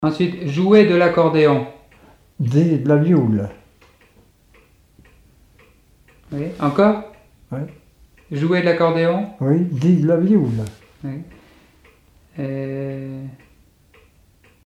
Appels d'animaux, locution vernaculaires, chansons et témoignages
Catégorie Locution